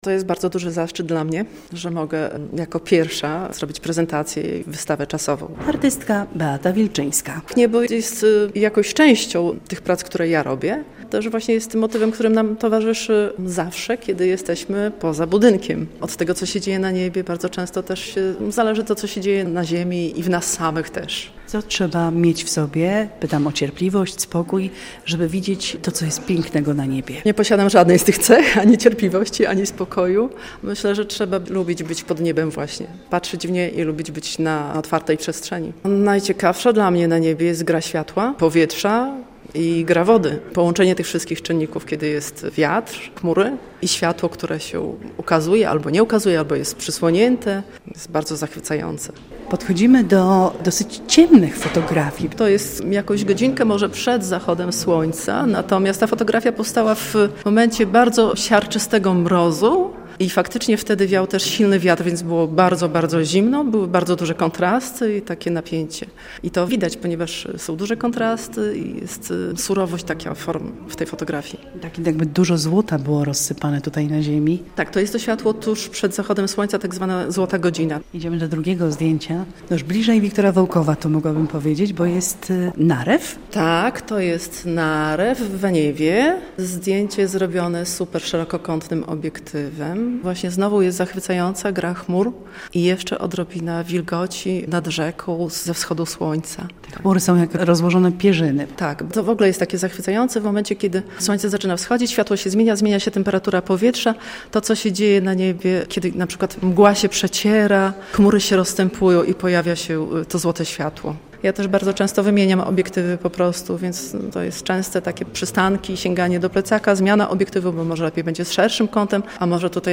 Pierwsza wystawa w Muzeum Wiktora Wołkowa - relacja